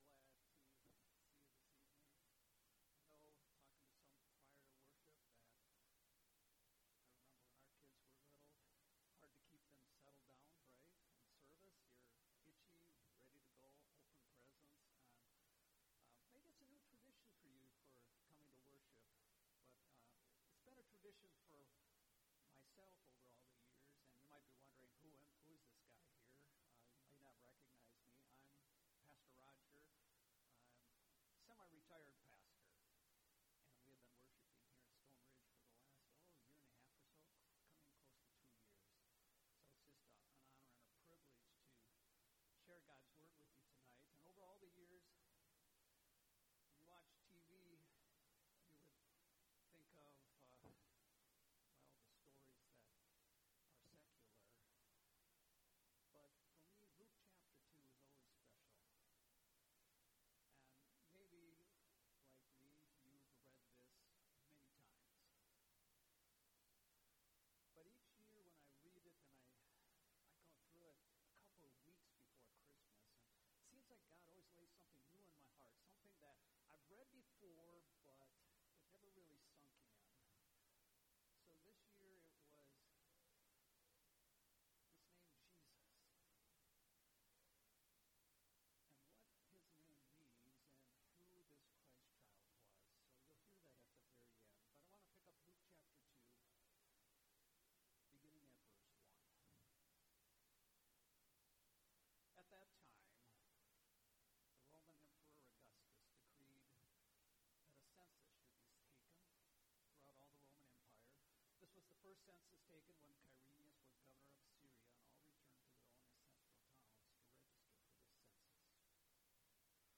Christmas Eve Service 2025 | Stone Ridge Community Church